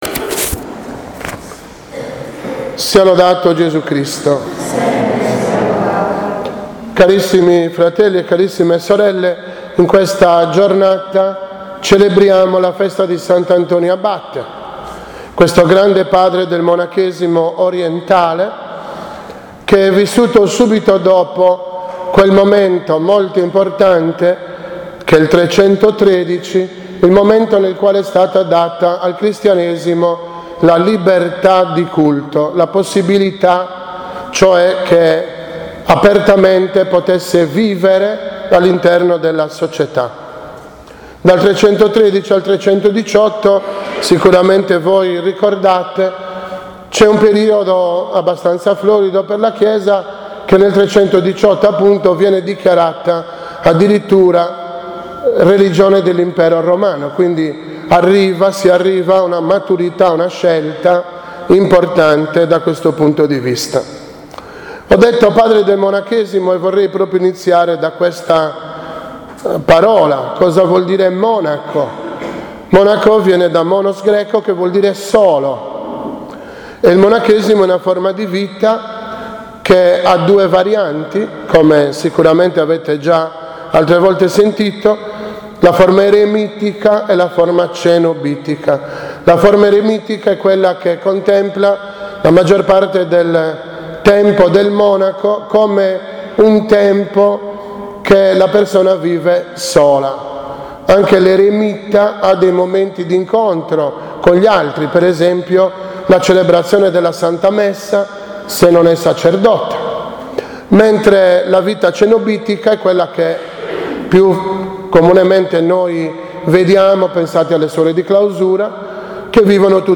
17.1.2018 OMELIA DELLA FESTA DELL’ABBATE S. ANTONIO